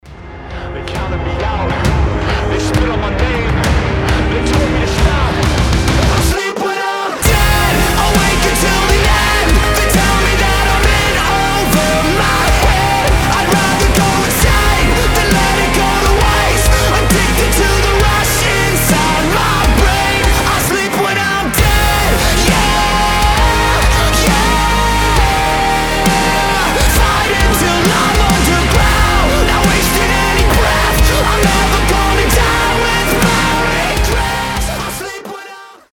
рок , взрывные , alternative rock
мощные , громкие